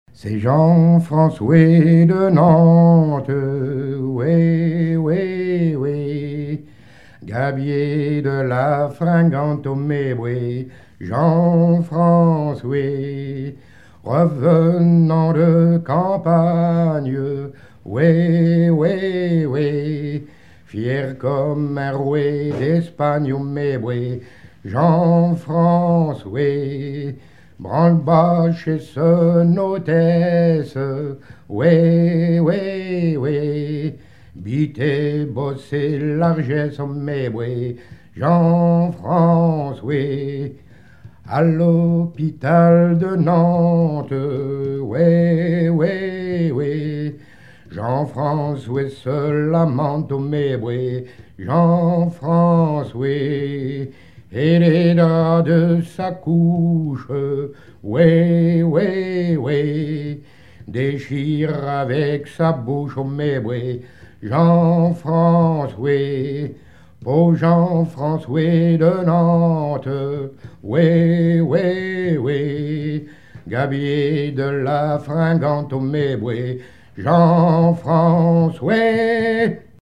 à virer au guindeau
chansons maritimes
Pièce musicale inédite